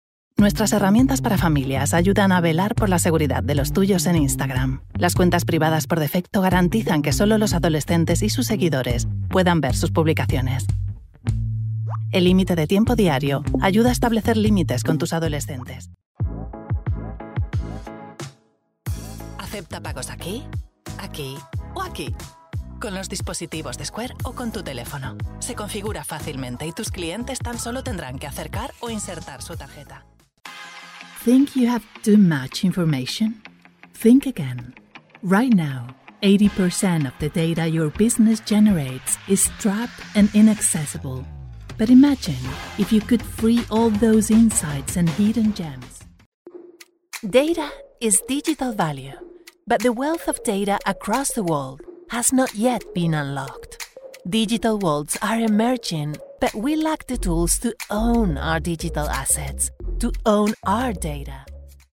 COMMERCIAL/BRAND
Professional Home studio with specific Voiceover equipment.
– Isolated Sound booth Vicoustic
– Sennheiser MK4 microphone
COMMERCIAL-BRAND.mp3